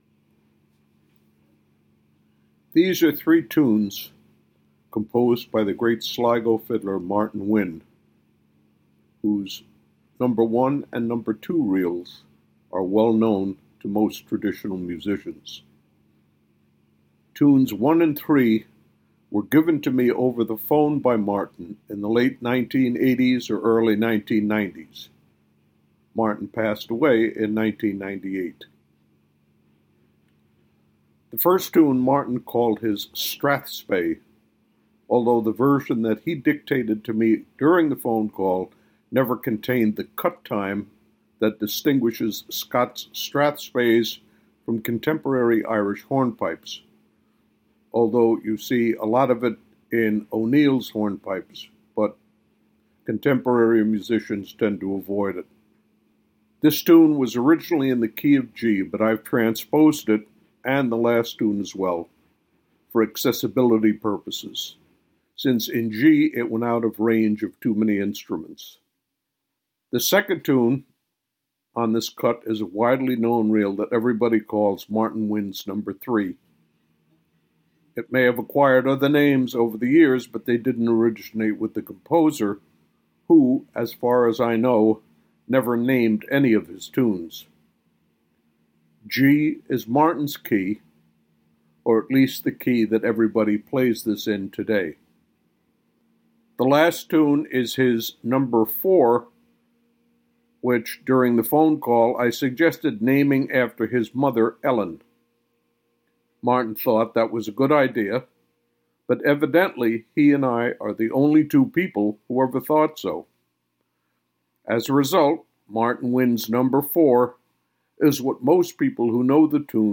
reels